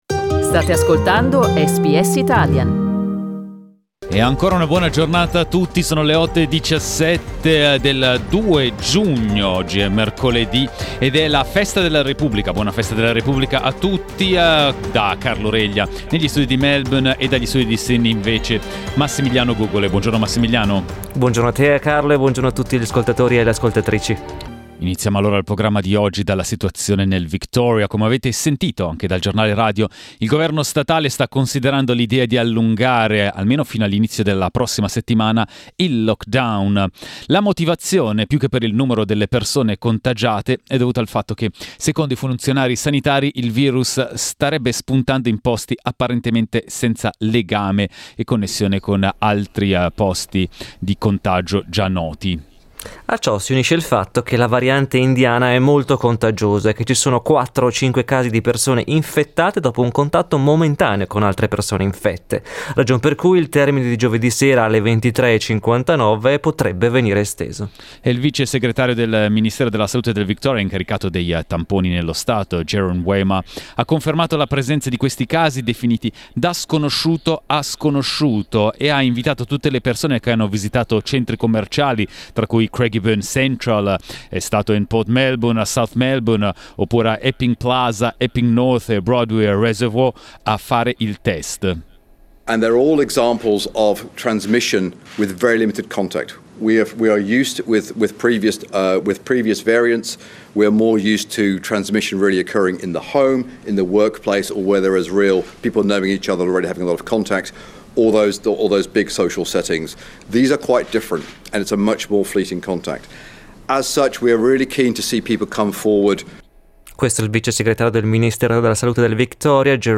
Ascolta il resoconto di inizio giornata di SBS Italian.